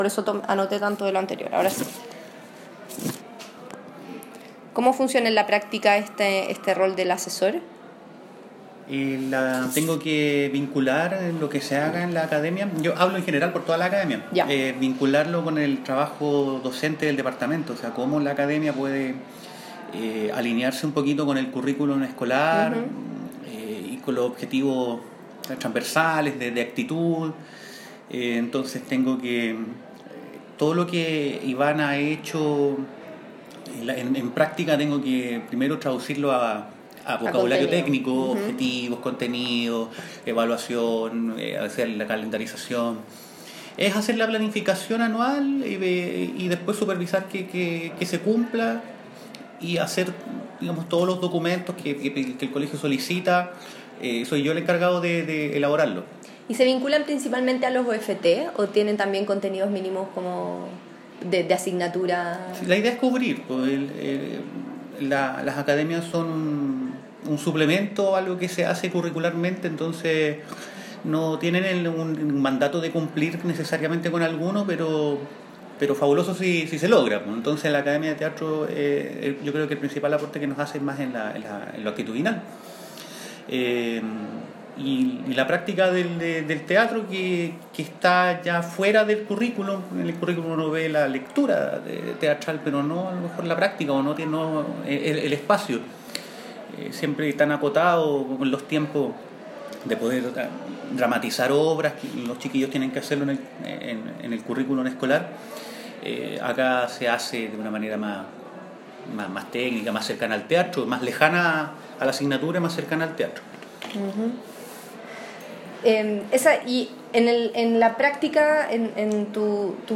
El inicio de la entrevista se cortó por problemas técnicos del sistema de grabación.